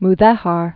(m-thĕhär)